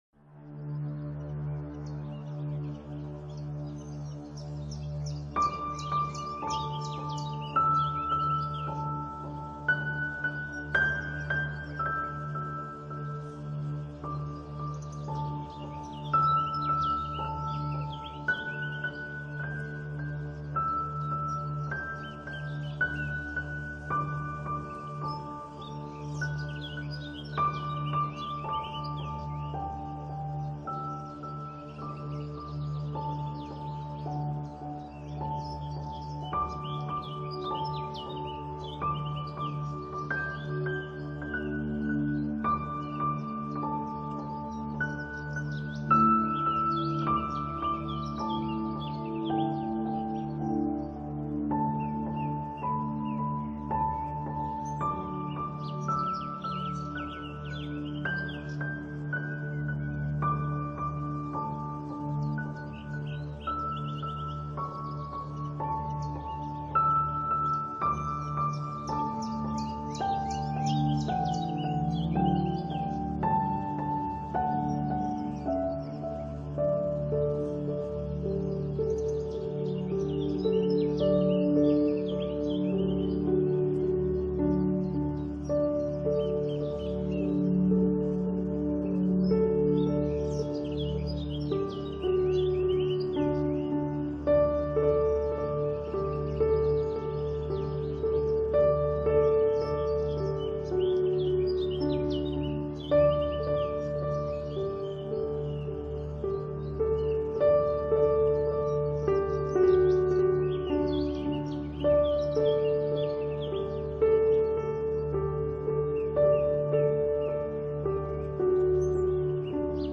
Swans-Running-on-Water-Birds-Flying-in-Slow-Motion.mp3